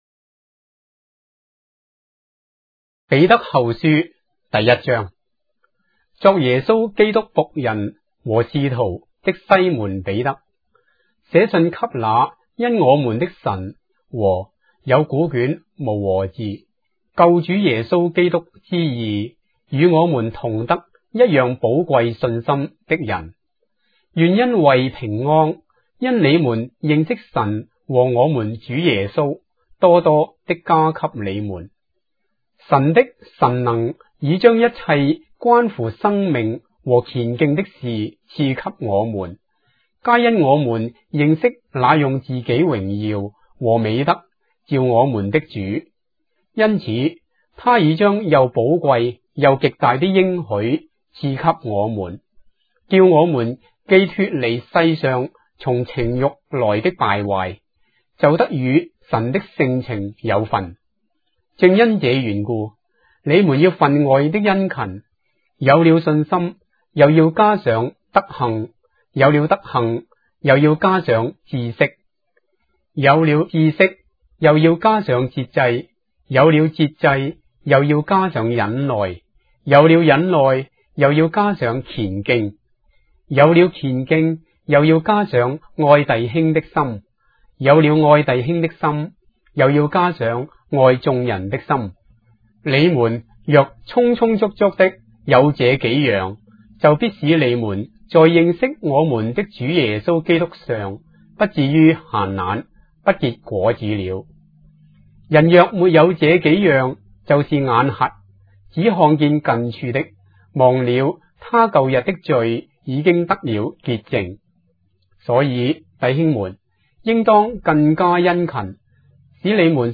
章的聖經在中國的語言，音頻旁白- 2 Peter, chapter 1 of the Holy Bible in Traditional Chinese